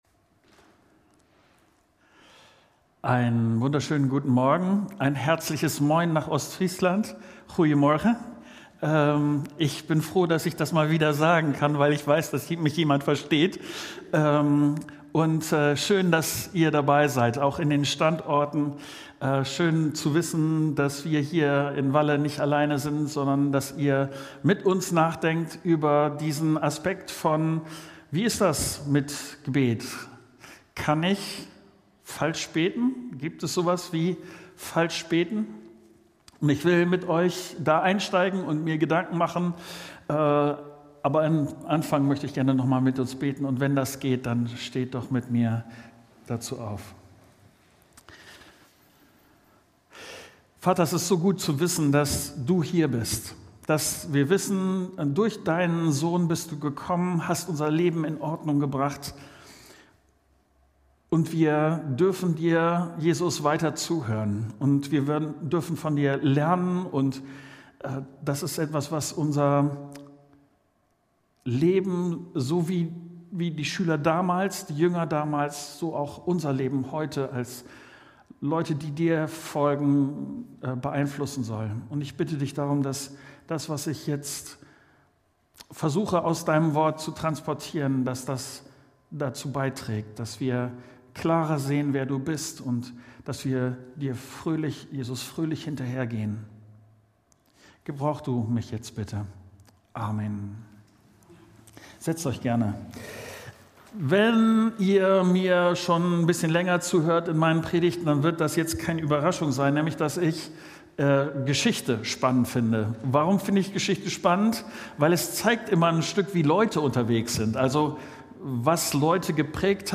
Bevor Jesus seinen Jüngern "Vater unser" beibringt, spricht er mit ihnen über eine gute Herzenshaltung beim Beten. Darum soll es in der Predigt gehen.